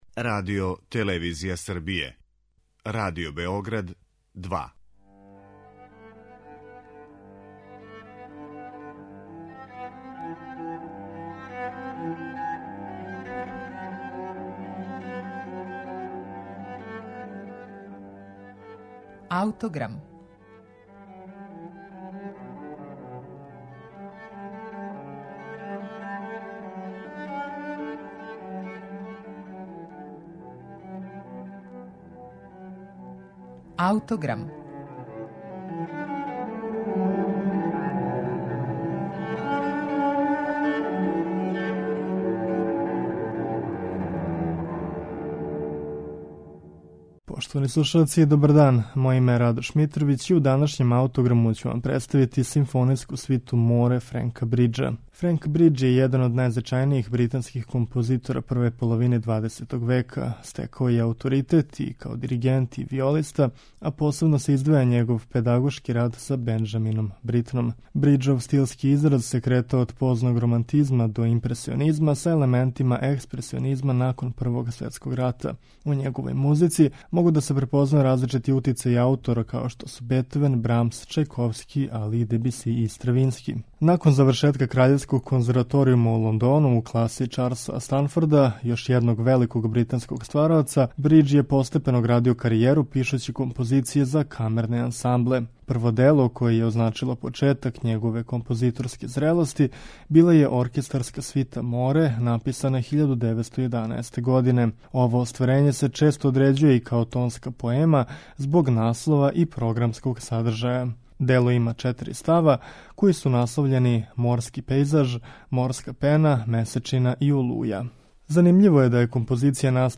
Прво зрело дело британског аутора Френка Бриџа је симфонијска свита Море. Настала је 1911. године и почива на претежно импресионистичком колориту.
Море Френка Бриџа представићемо вам у извођењу Би-Би-Си-јевог Националног оркестра из Велса и диригента Ричарда Хикокса.